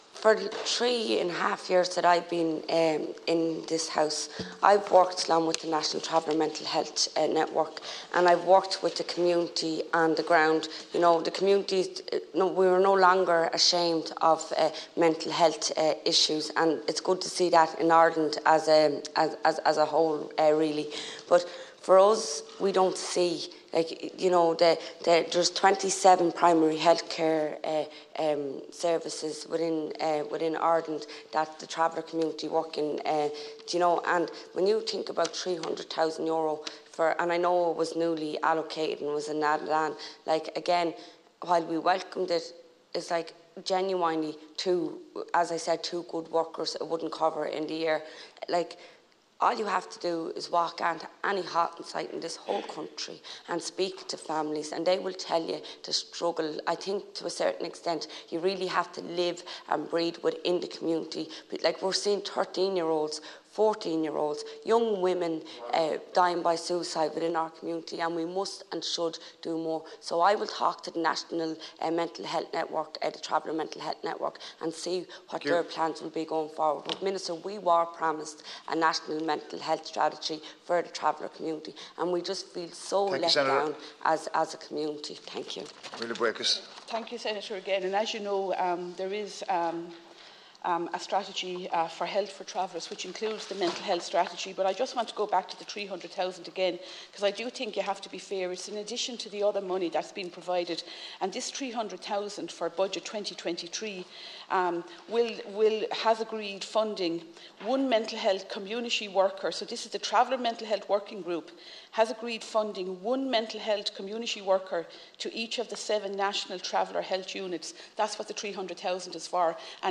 Donegal Senator Eileen Flynn raised the issue with Minister Mary Butler in the Seanad this morning.